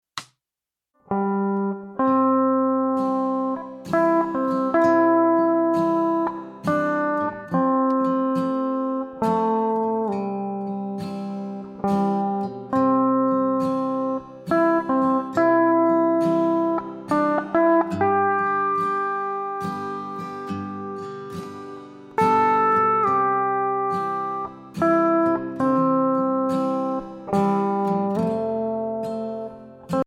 Voicing: Steel Guit